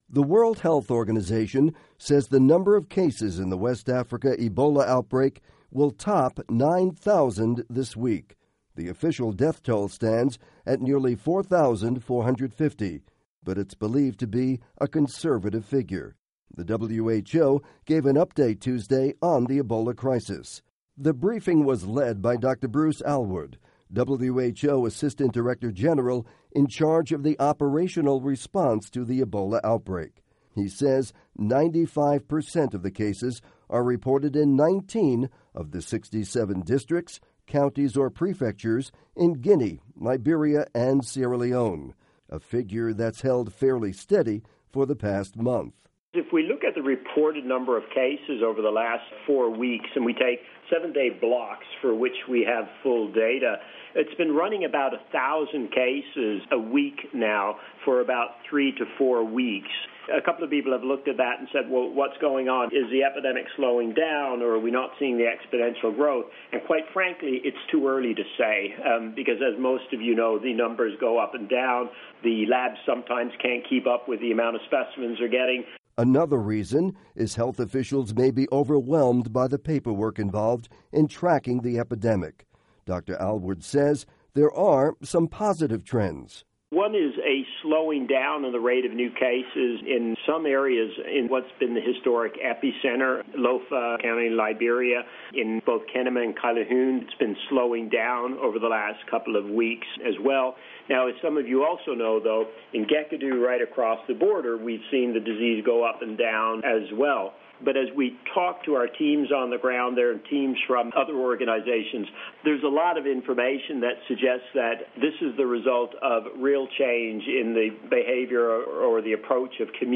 report on WHO Ebola update